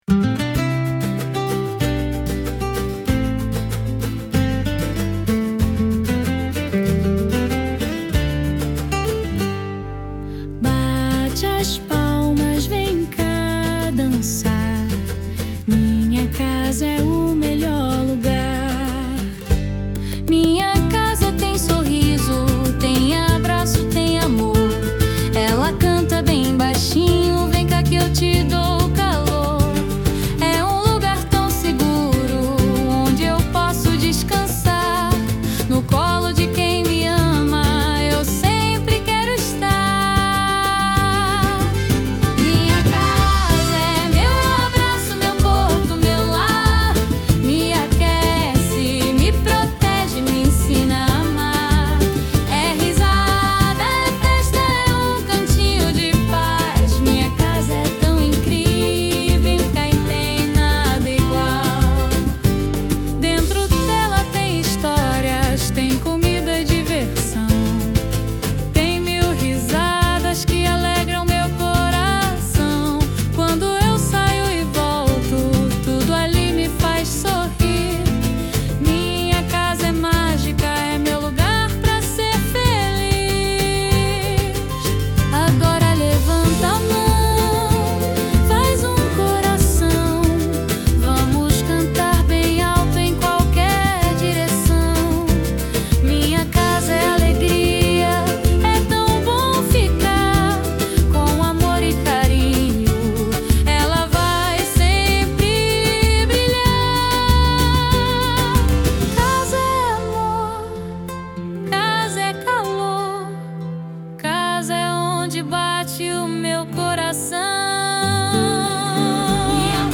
EstiloNew Age